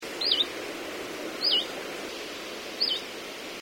The sweeoo call was also dominant in North-Western Estonia this autumn.
This call was recorded on 25 Septermber 2011 at Haversi, Noarootsi, Läänemaa.